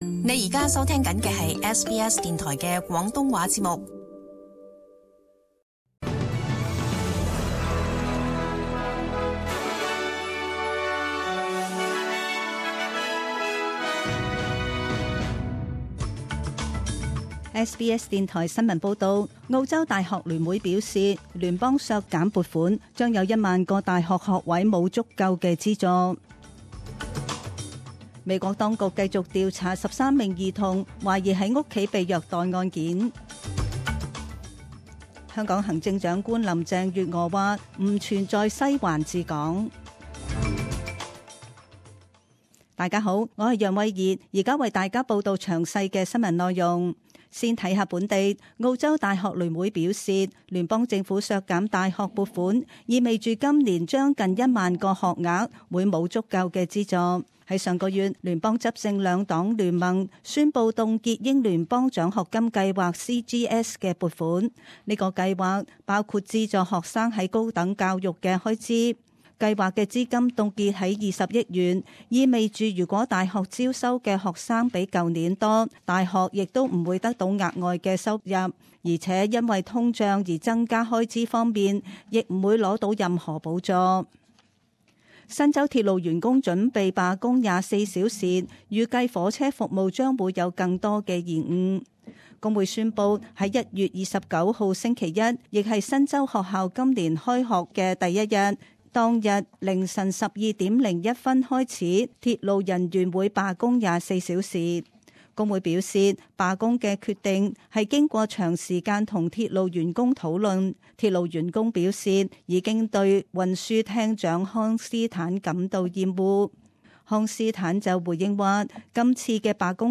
SBS Cantonese 10am news Source: SBS